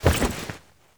foley_jump_movement_throw_02.wav